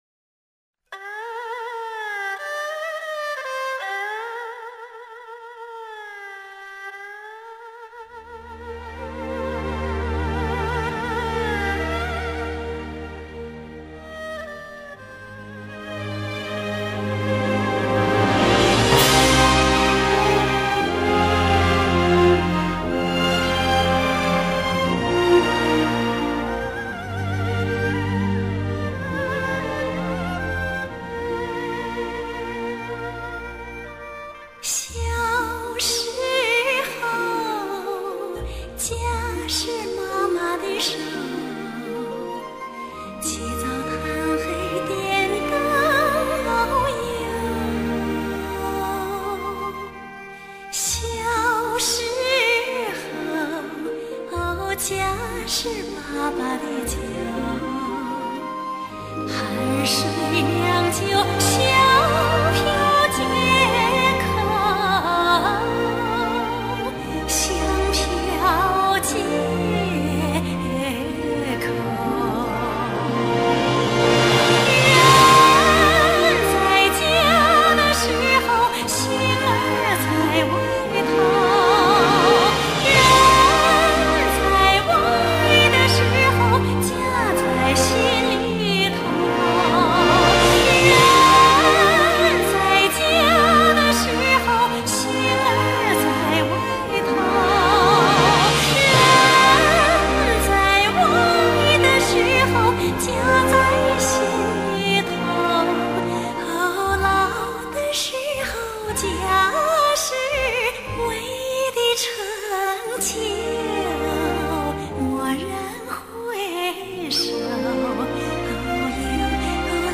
没有花里胡哨的东西